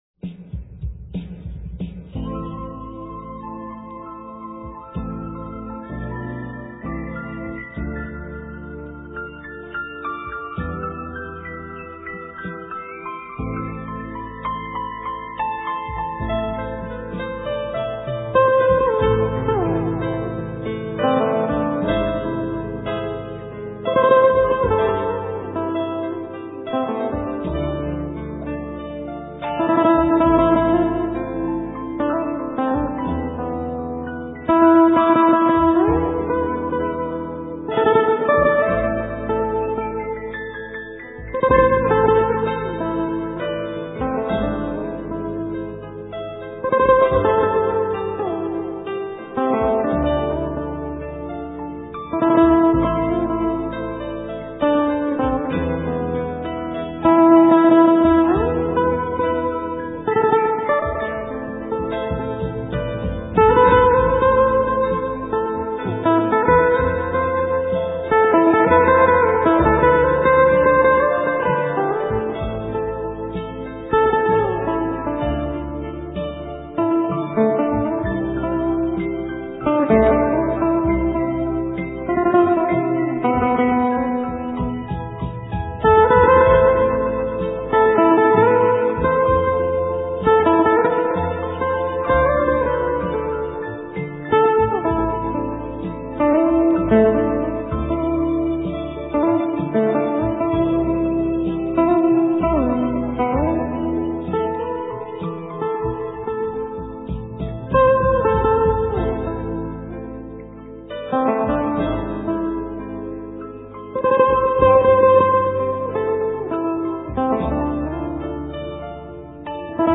* Ca sĩ: Không lời
* Thể loại: Nhạc Việt